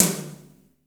DANCETOMM.wav